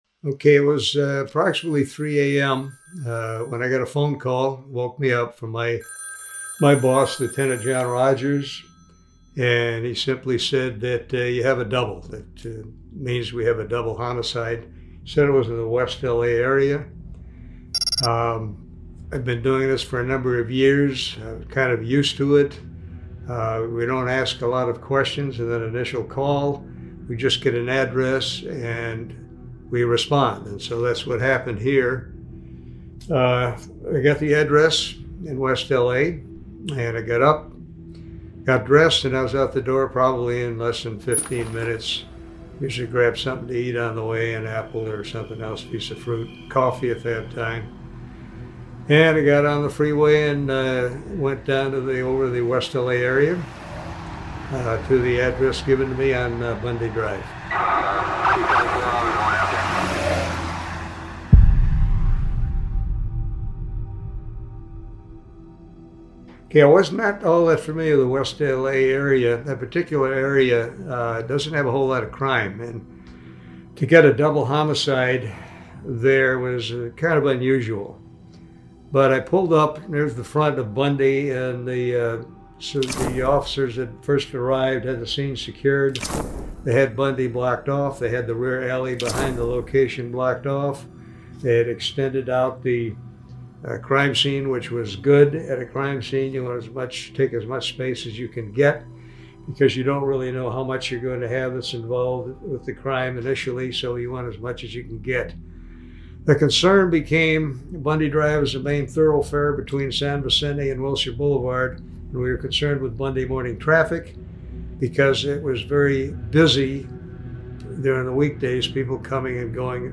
Detective Tom Lange Interview Part 1
L.A.P.D. Detective Tom Lange sits down for a candid look into his investigation of O.J. Simpson for the brutal murders of Ron Goldman and Nicole Brown Simpson.